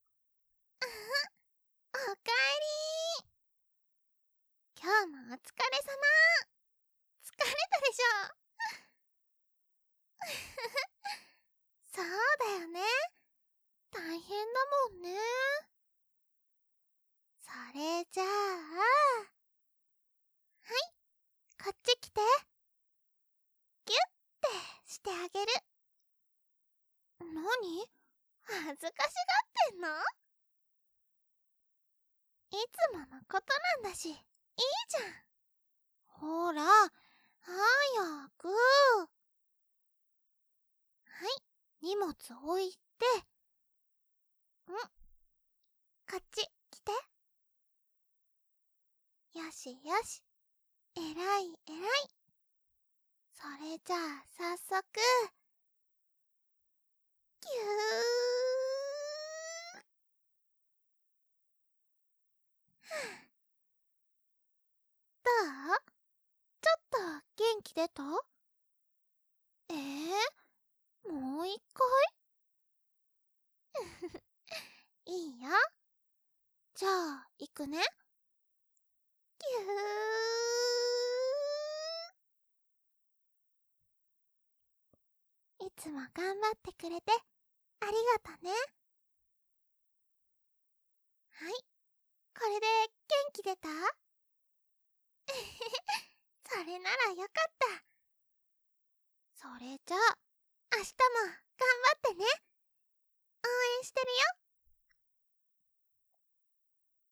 纯爱/甜蜜 日常/生活 温馨 萌 健全 治愈 皆大欢喜 催眠音声